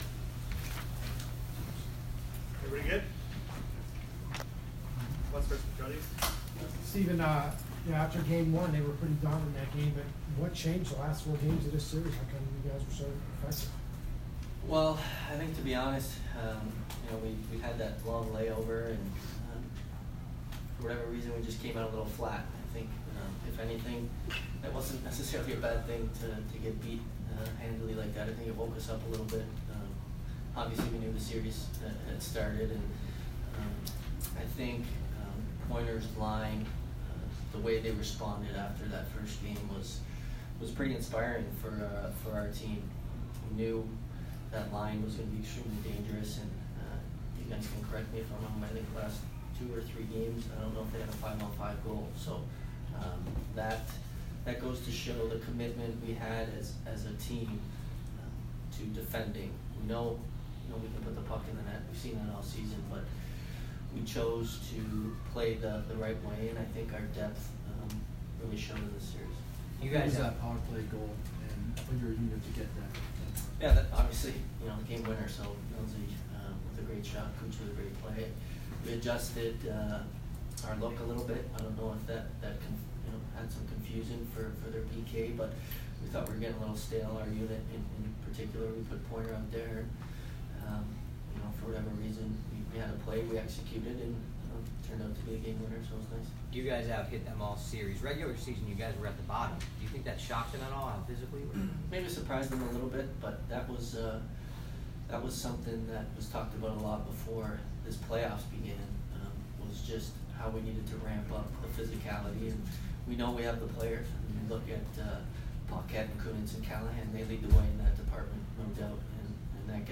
Steven Stamkos post-game 5/6